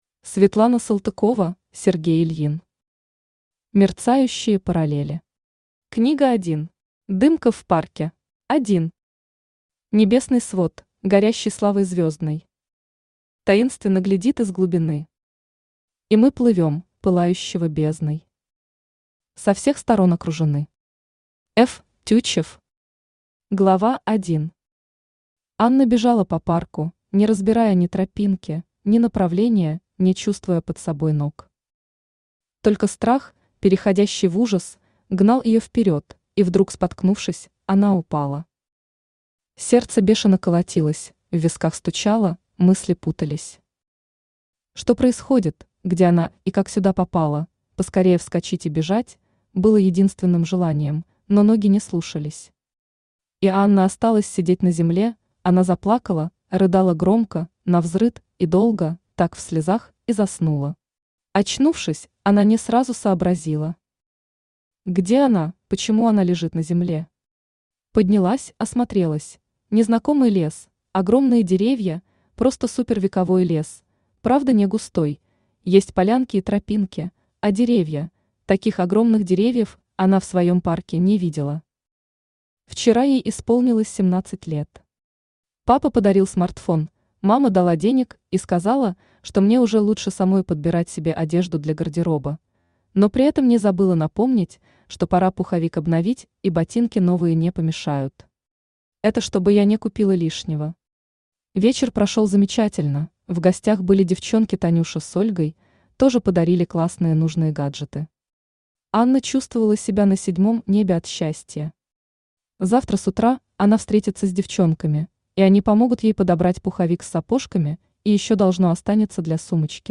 Дымка в парке Автор Светлана Николаевна Салтыкова Читает аудиокнигу Авточтец ЛитРес.